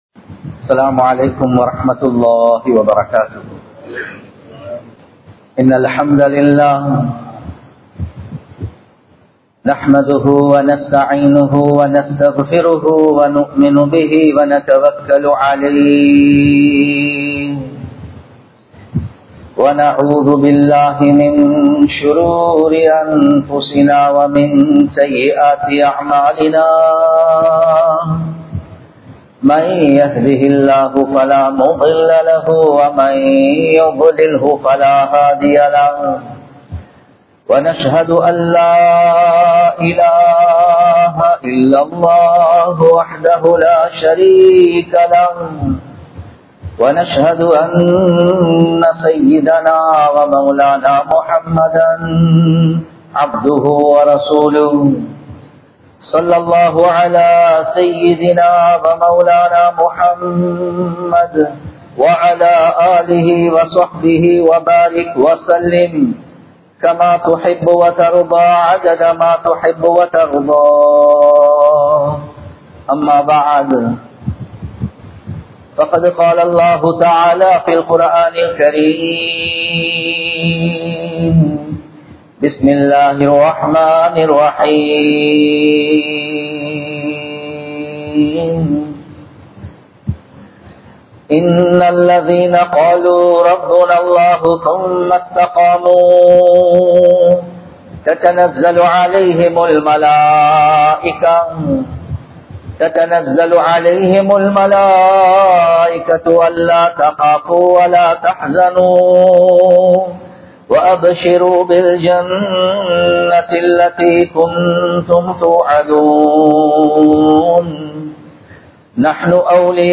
பிரச்சினைகளுக்கான 04 தீர்வுகள் | Audio Bayans | All Ceylon Muslim Youth Community | Addalaichenai
Colombo 12, Aluthkade, Muhiyadeen Jumua Masjidh